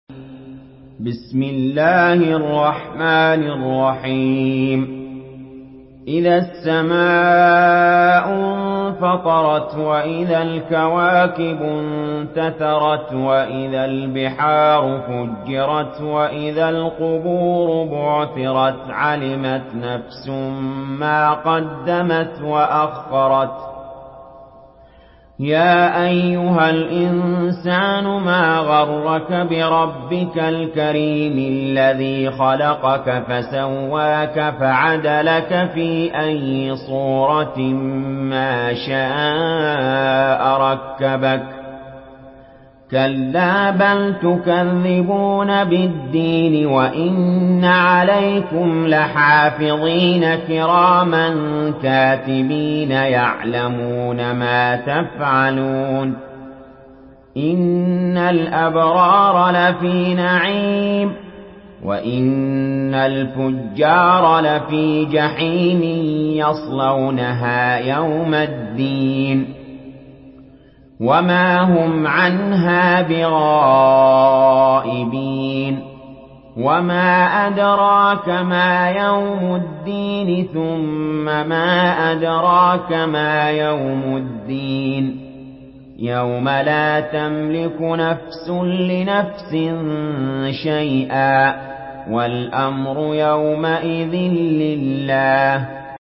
Surah Al-Infitar MP3 by Ali Jaber in Hafs An Asim narration.
Murattal